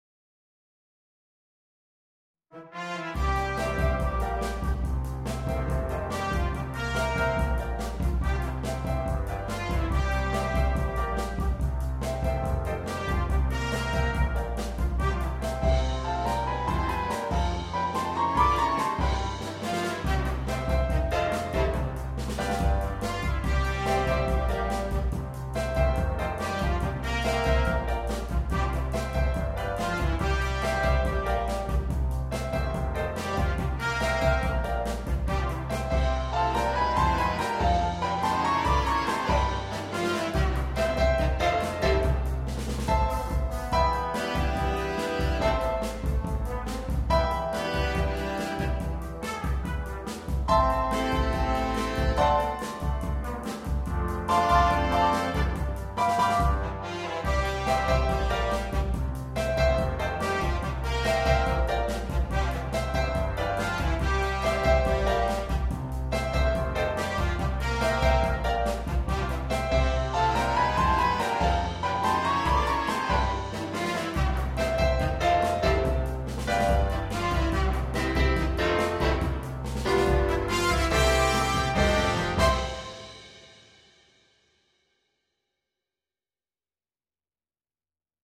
для биг-бэнда.